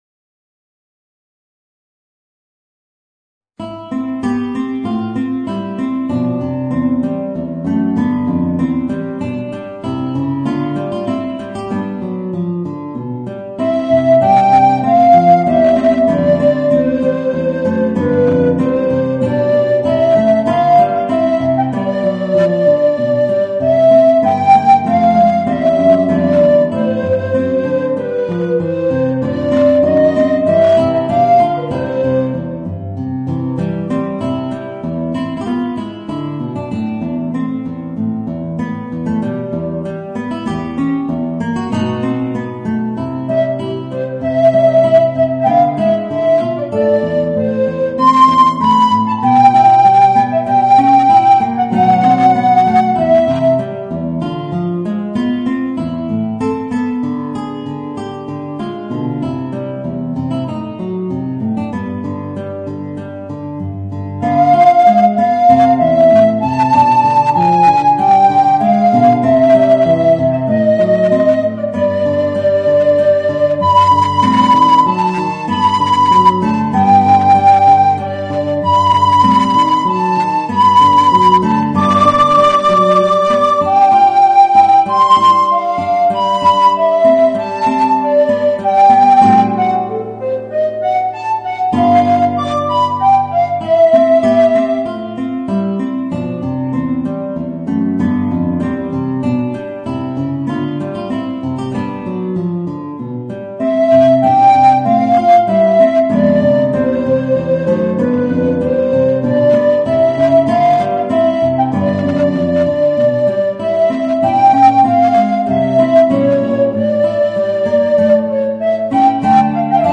Voicing: Alto Recorder and Guitar